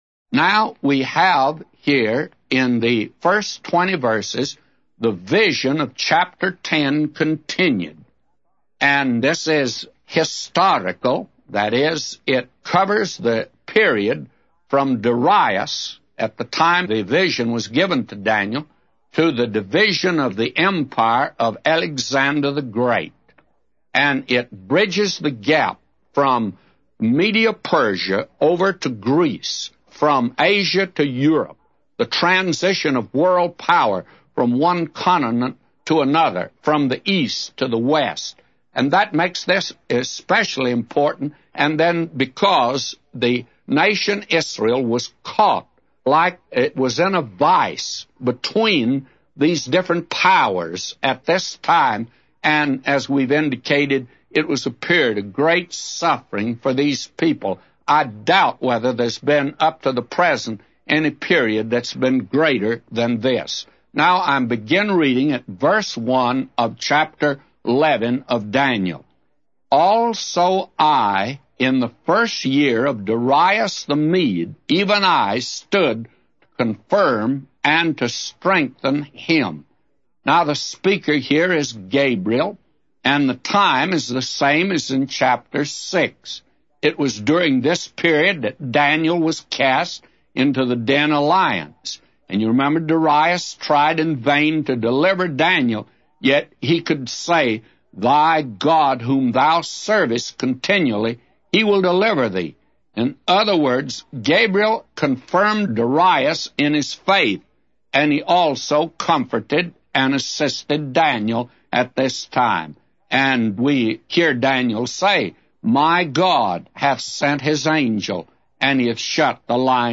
A Commentary By J Vernon MCgee For Daniel 11:1-999